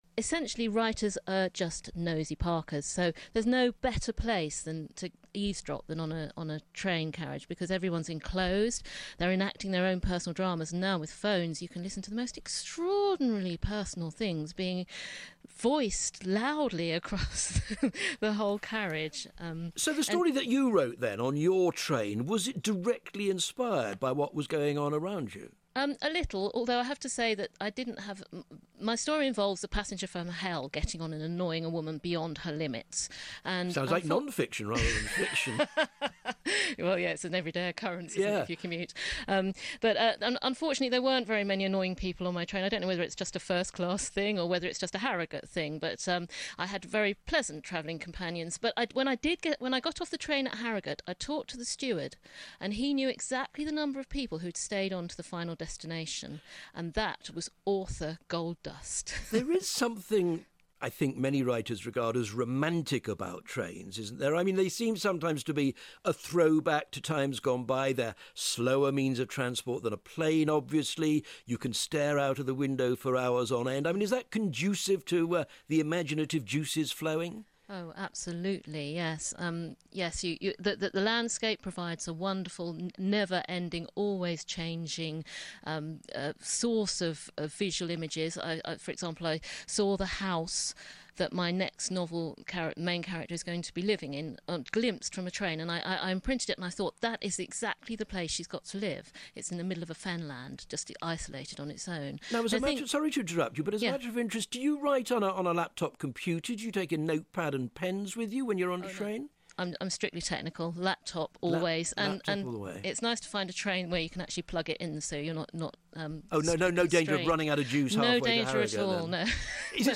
British crime novelist Julia Crouch speaks to Robin Lustig on being a writer in residence on a train.